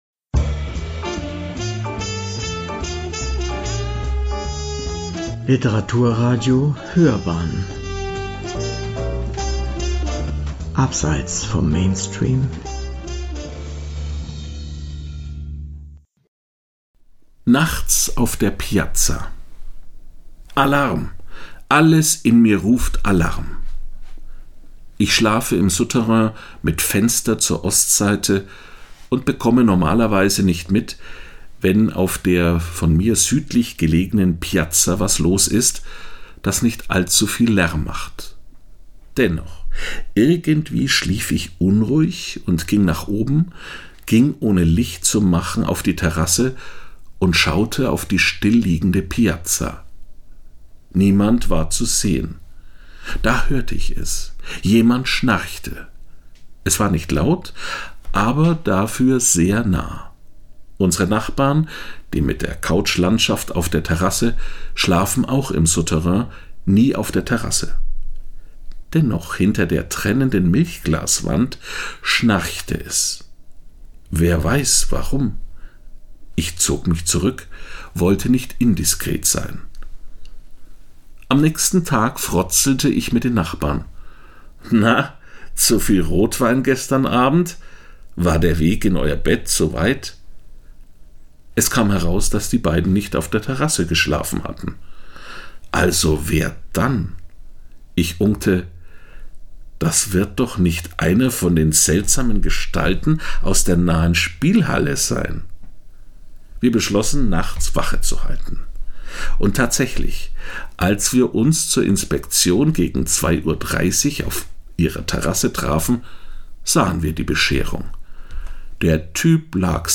Piazza an der Bo “Nachts auf der Piazza” – eine Kolumne
Sprecher: